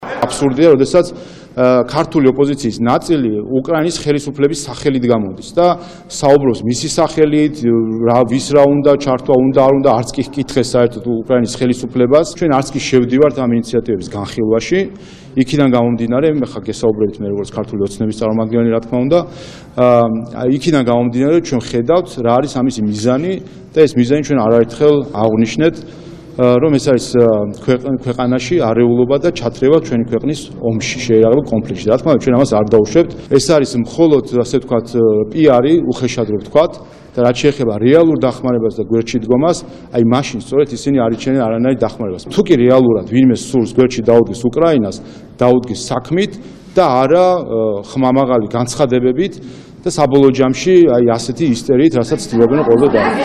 შალვა პაპუაშვილის ხმა